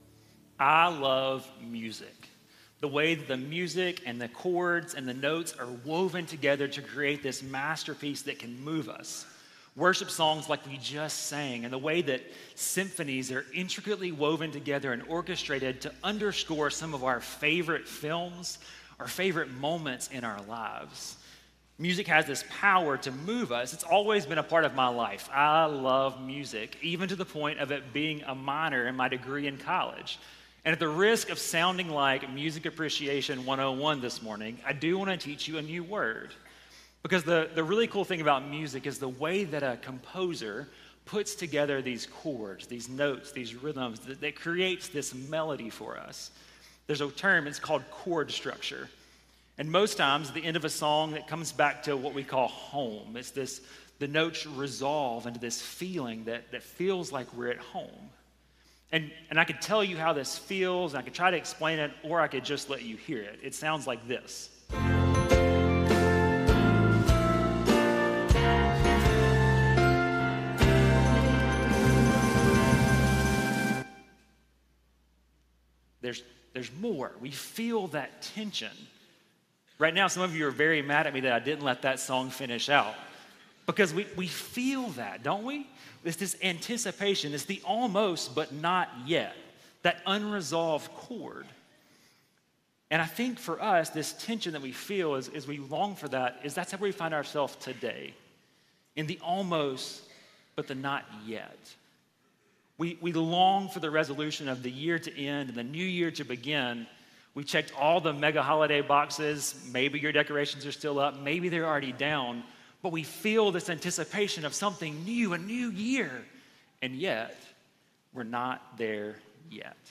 Sermon Series: Family Service